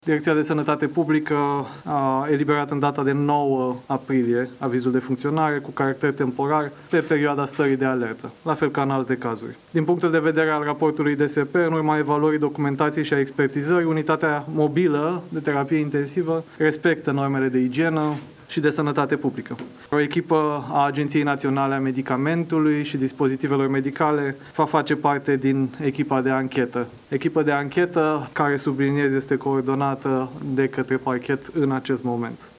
Ministrul sănătății, Vlad Voiculescu, a cerut DSP avizele de funcționare pentru toate unitățile mobile de terapie intensivă de tipul celui de la spitalul Victor Babeș, unde o avarie la instalația de oxigen a dus la decesul a 3 presoane. Este prima declarație facută de ministru după tragedia de aseară: